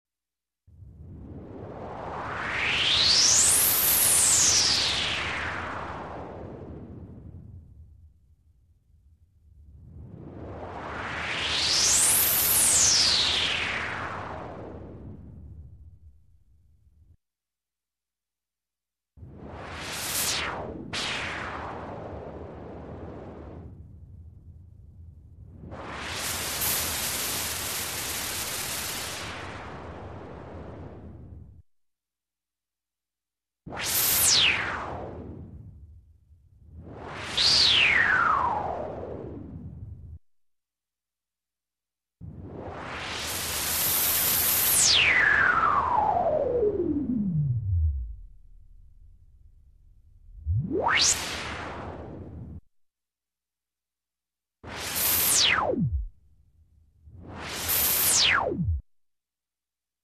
Электронный свист
Тут вы можете прослушать онлайн и скачать бесплатно аудио запись из категории «Космос, вселенная».